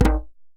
DJEM.HIT14.wav